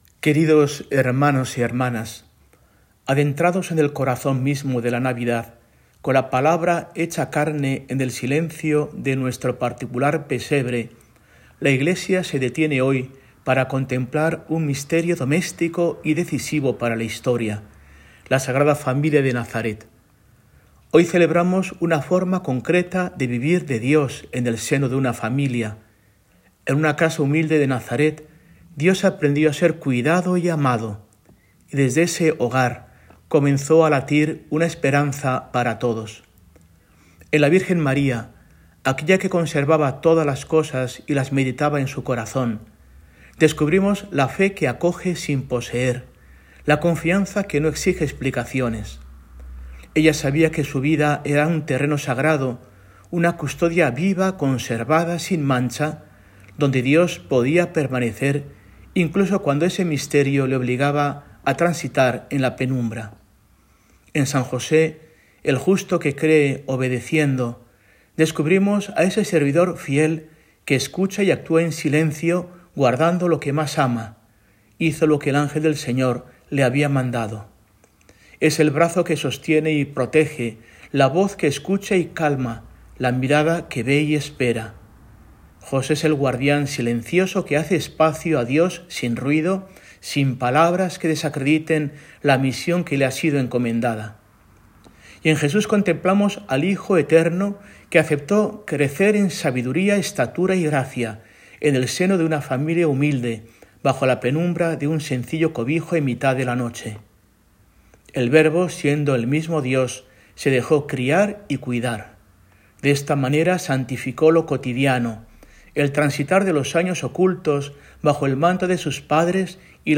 Arzobispo de Burgos